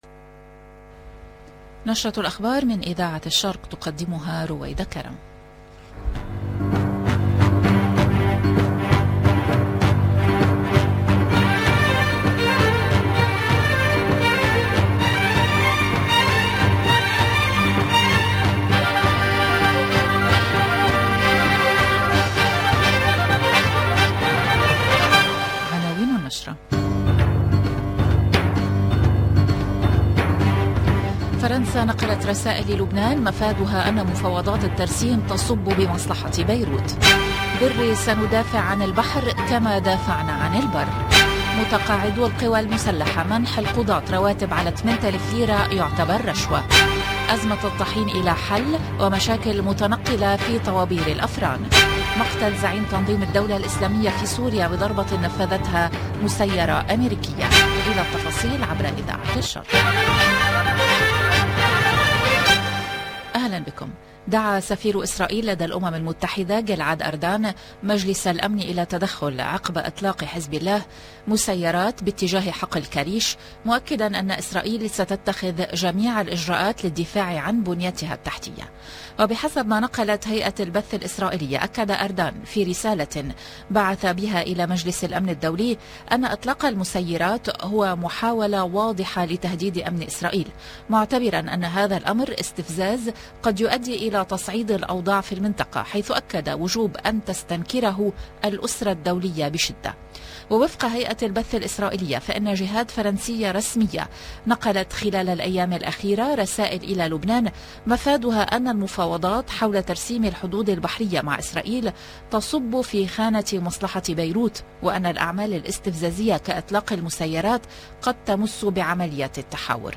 LE JOURNAL DU LIBAN DU SOIR DU 12/07/22